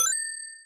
CollectCoin
coin collect game ring sonic sound effect free sound royalty free Sound Effects